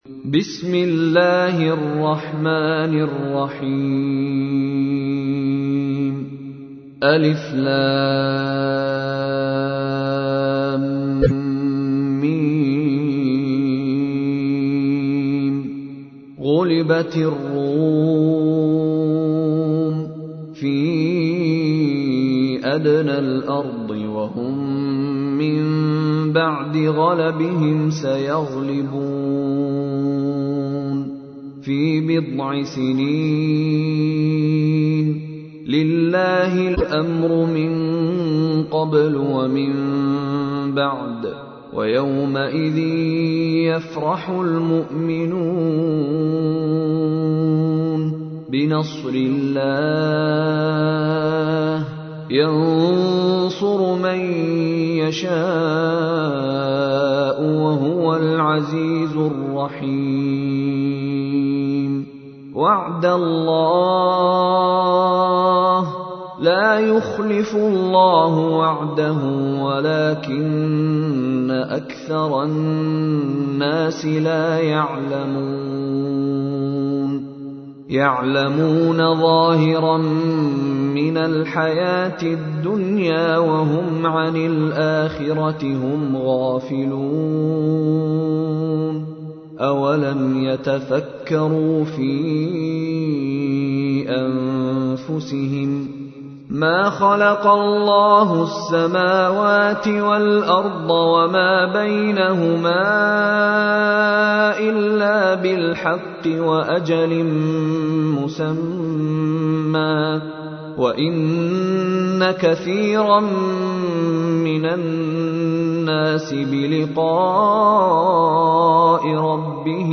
تحميل : 30. سورة الروم / القارئ مشاري راشد العفاسي / القرآن الكريم / موقع يا حسين